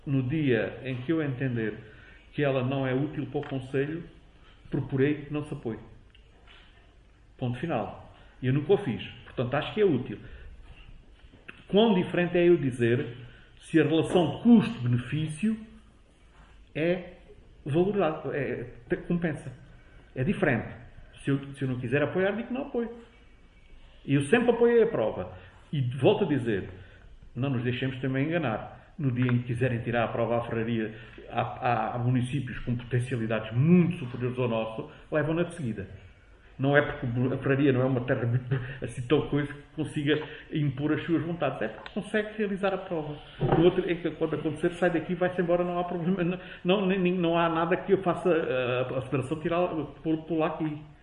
O Raid foi tema em discussão na última reunião de Câmara Municipal de Gavião.
ÁUDIO | PRESIDENTE DA CÂMARA MUNICIPAL, JOSÉ PIO: